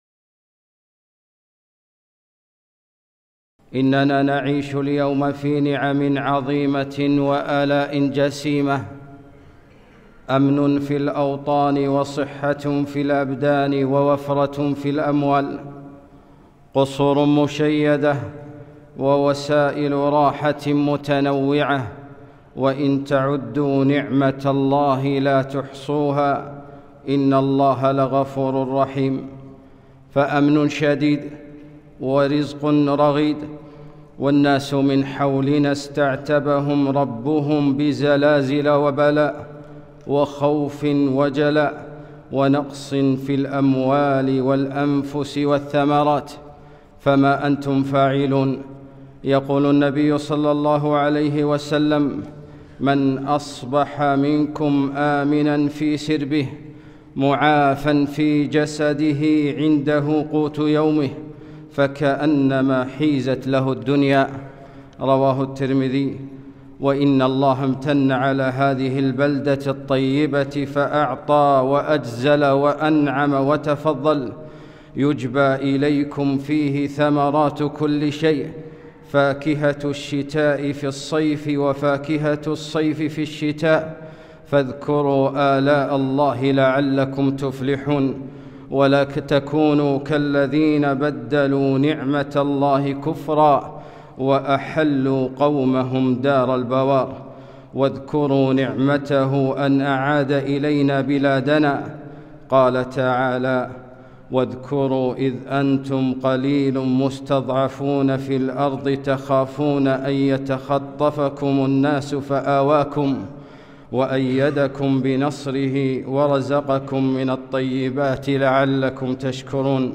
خطبة - الوطن والجماعة